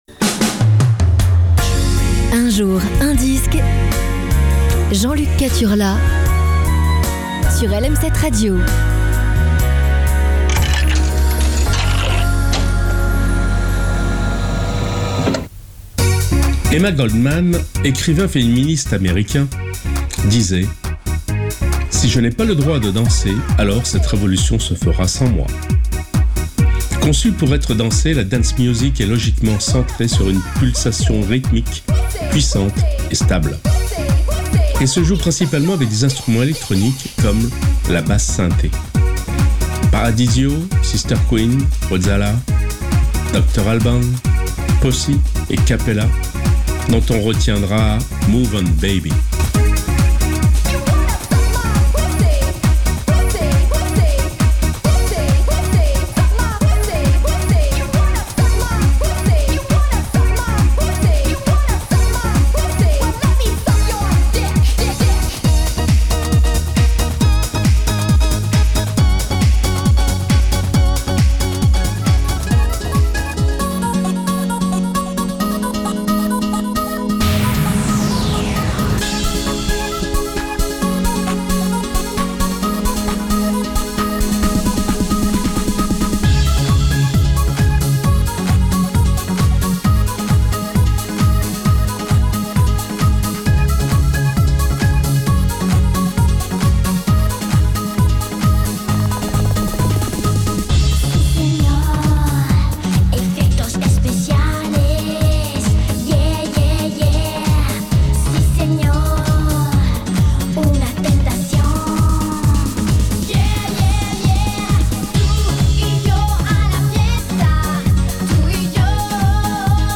aujourd'hui c'est DANCE